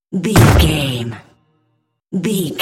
Cinematic stab hit trailer
Sound Effects
In-crescendo
Atonal
heavy
intense
dark
aggressive